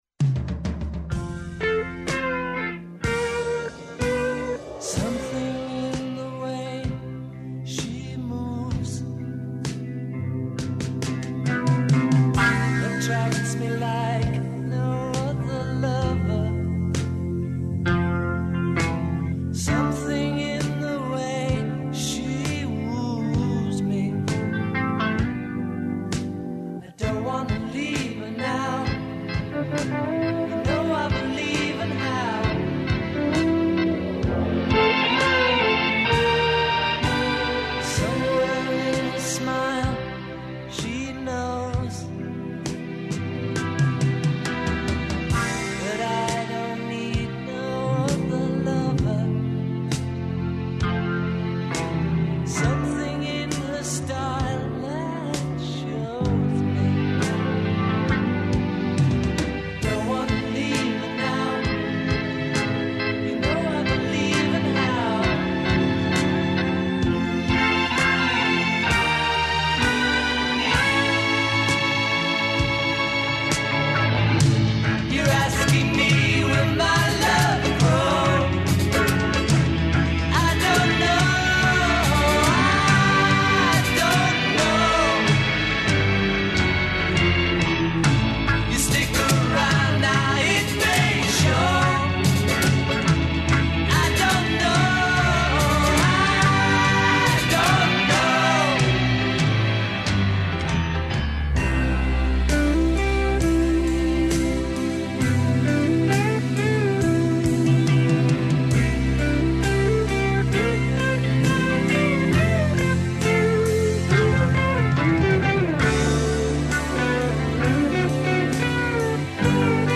Гости у студију